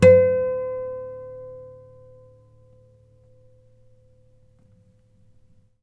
harmonic-02.wav